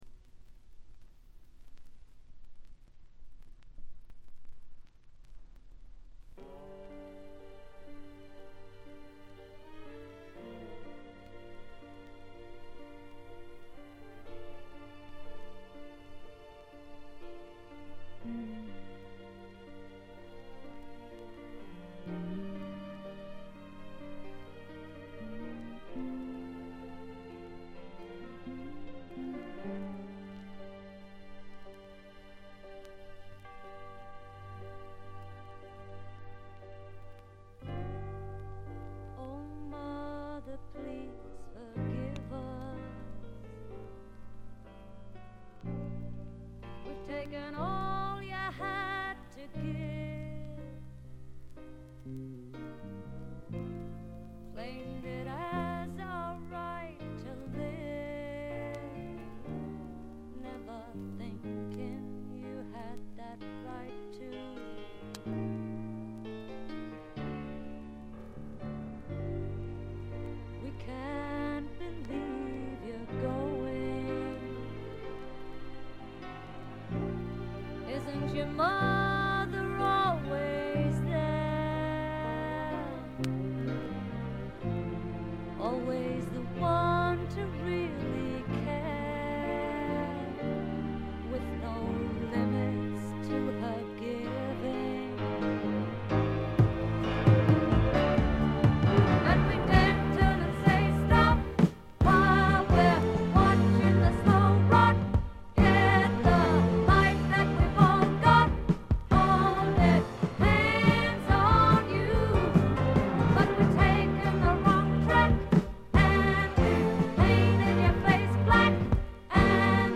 静音部での細かなチリプチ。
試聴曲は現品からの取り込み音源です。